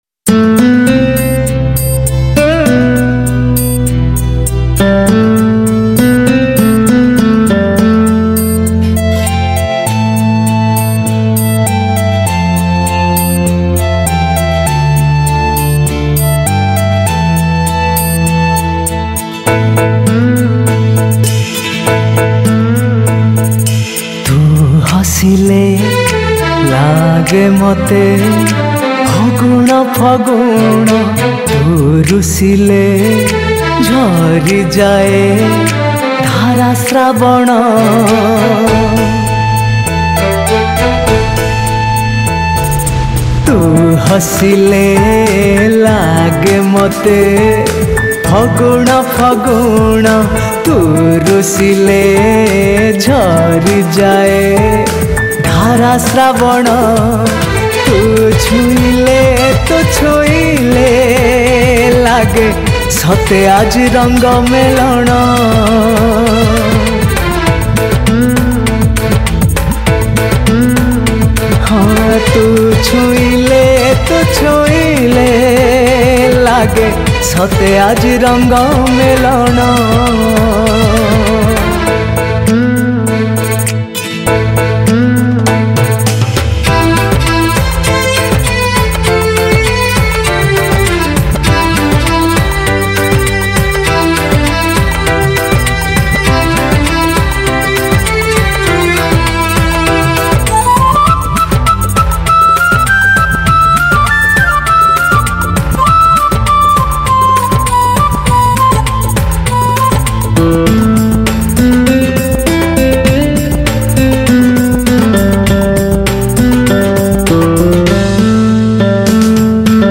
Odia Romantic Song
Odia Album Songs